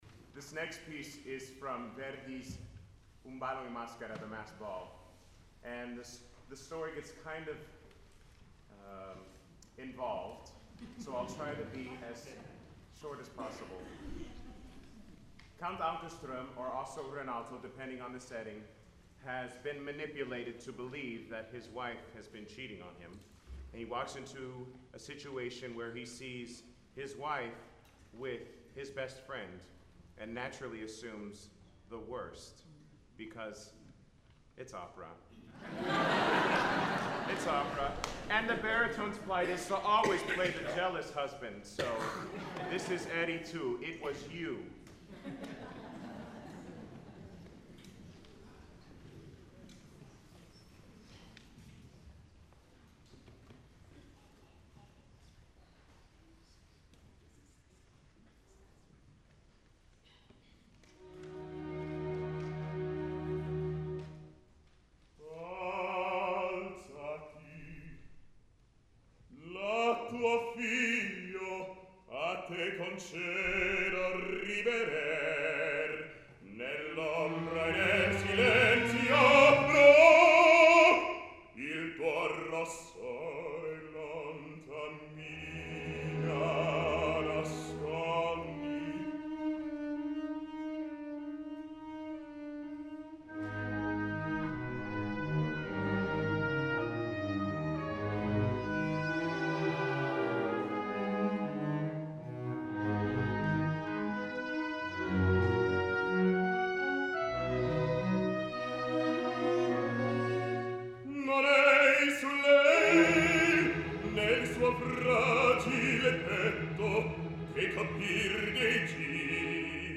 Concert recordings
Baritone Soloist